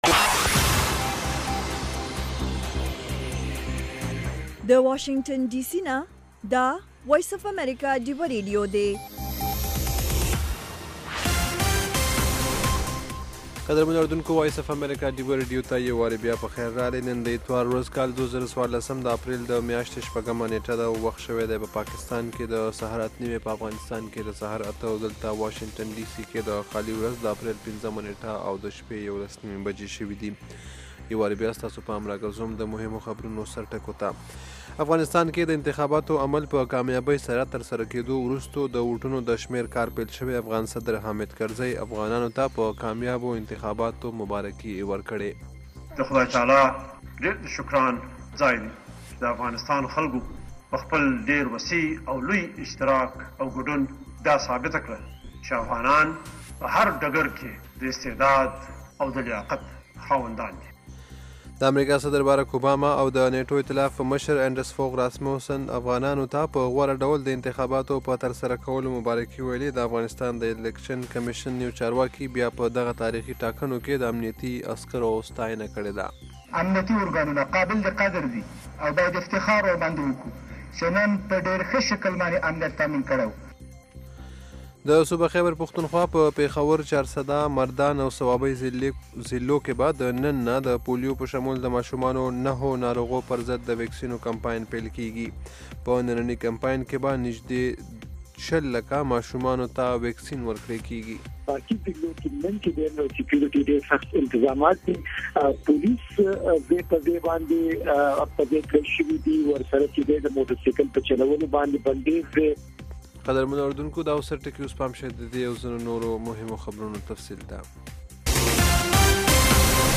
خبرونه - 0330